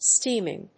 音節stéam・ing 発音記号・読み方
/ˈstiːm.ɪŋ(英国英語), ˈstimɪŋ(米国英語)/